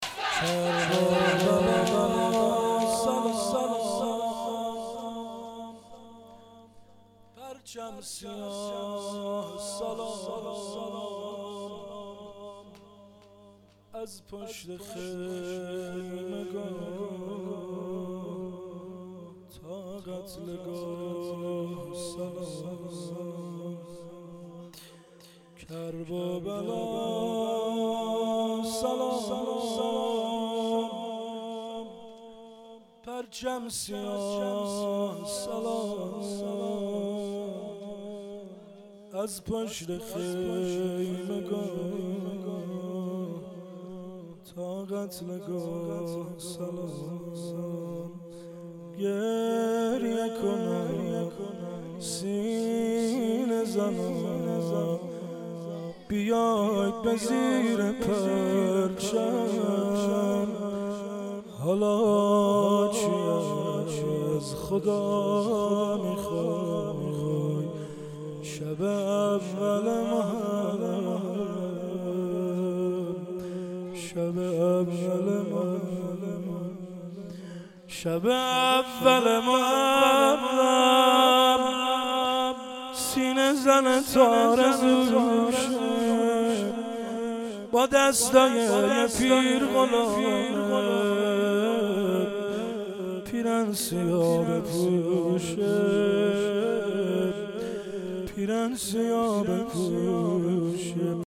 شعرخوانی